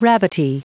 Prononciation du mot rabbity en anglais (fichier audio)
Prononciation du mot : rabbity